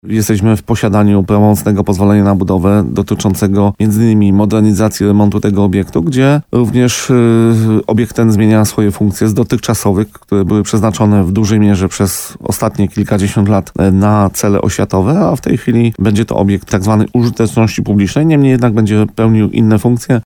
Wciąż będzie to obiekt tzw. użyteczności publicznej – mówi burmistrz Paweł Fyda.